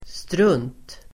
Uttal: [strun:t]